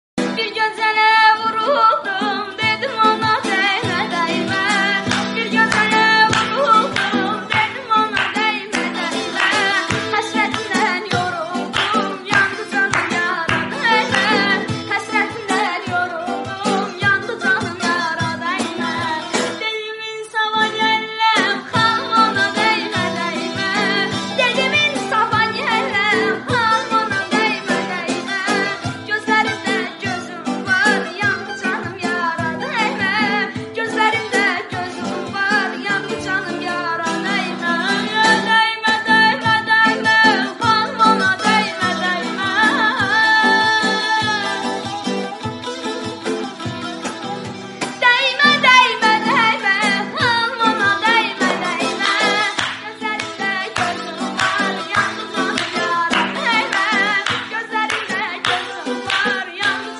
ریمیکس اینستا تند بیس دار سیستمی